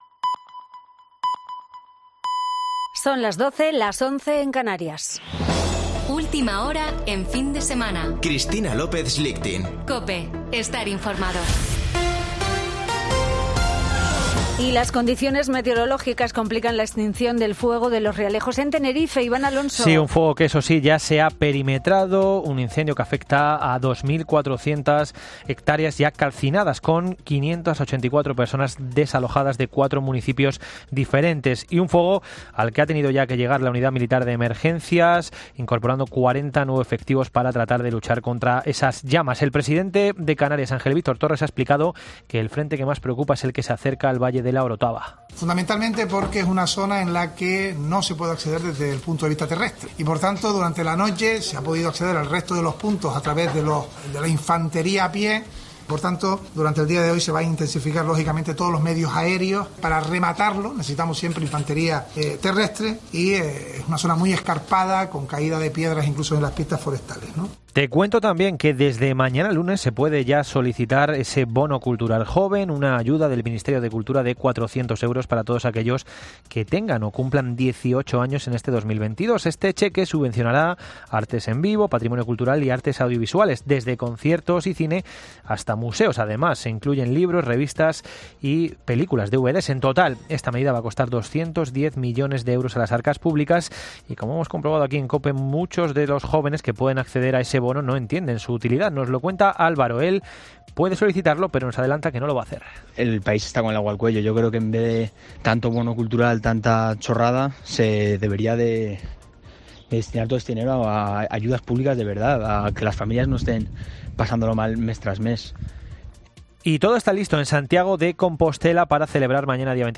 Boletín de noticias de COPE del 24 de julio de 2022 a las 12:00 horas